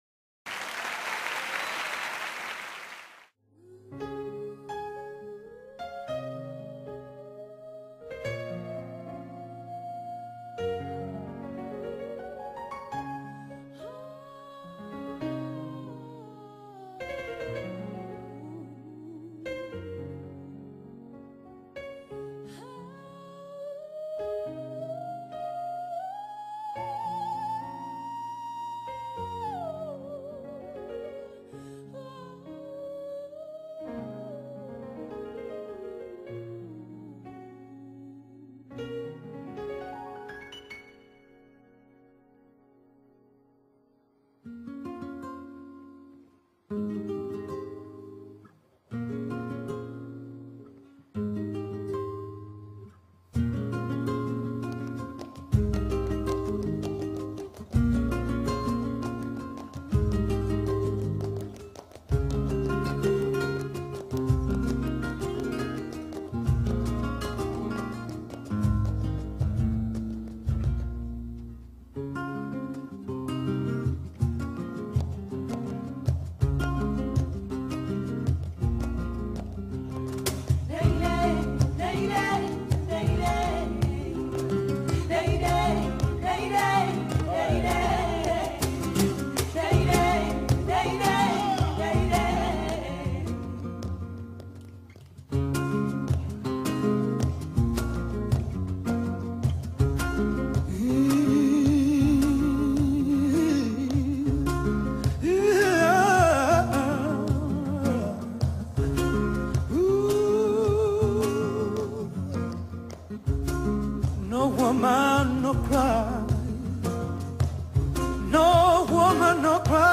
SOULERÍA